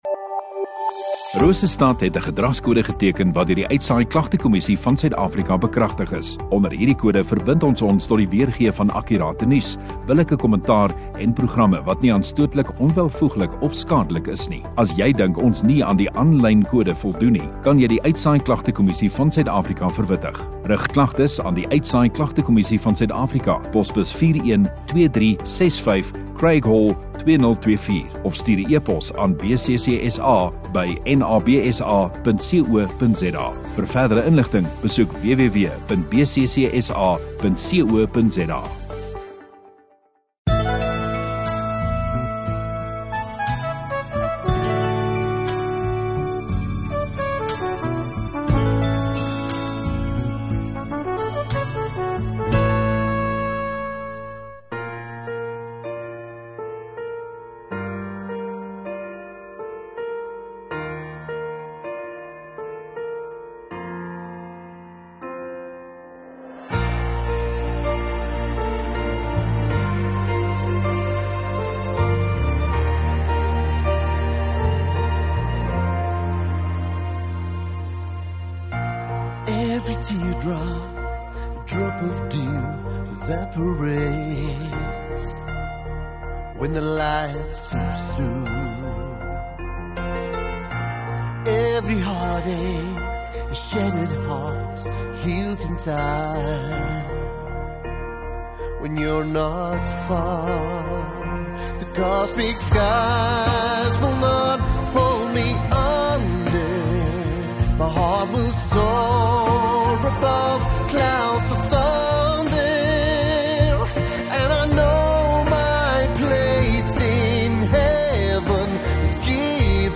28 Sep Sondagoggend Erediens